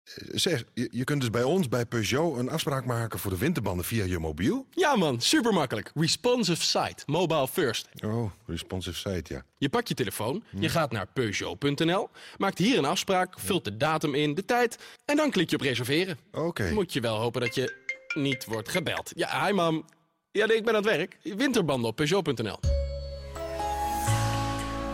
Hieronder enkele radiocommercials: